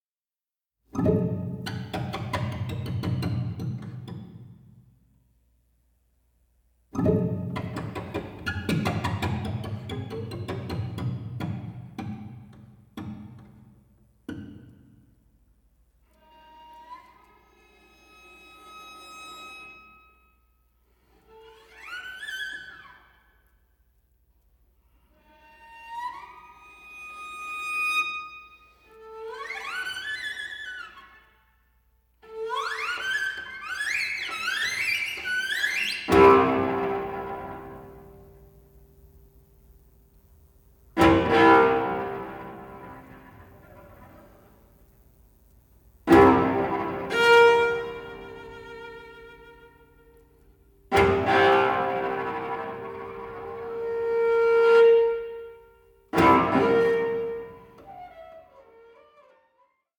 for solo cello